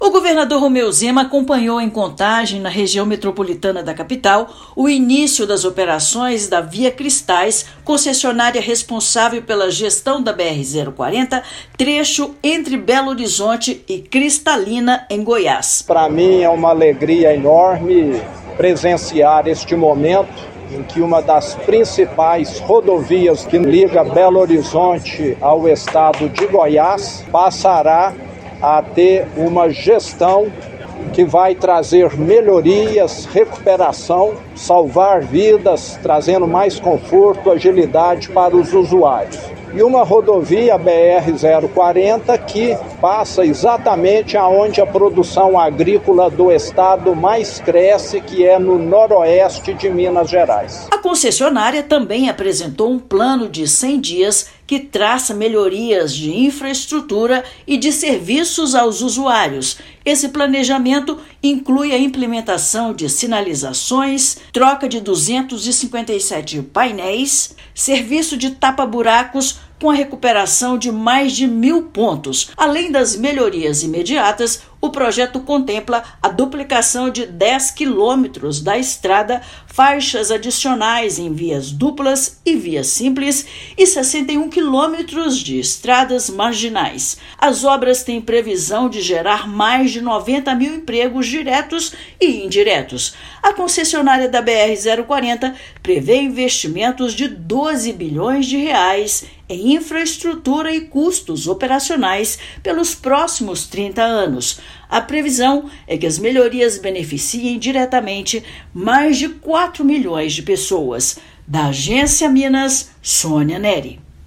Trecho entre Belo Horizonte e Cristalina (GO) vai receber uma série de melhorias contribuindo para a segurança viária e desenvolvimento econômico da região. Ouça matéria de rádio.